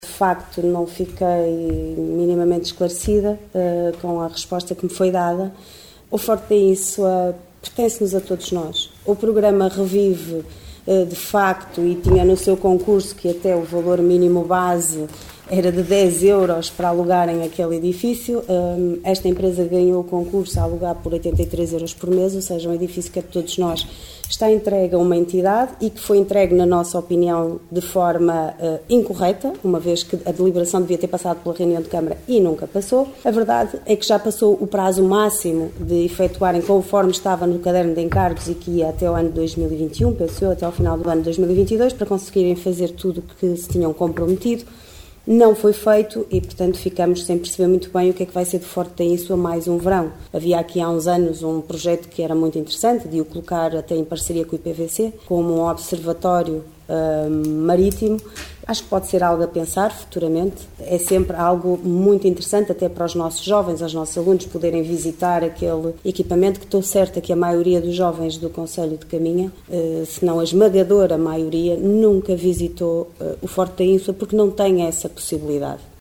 Rui Lages, Presidente da Câmara Municipal de Caminha
Futuro incerto do Forte da Ínsua em análise na última reunião do executivo camarário.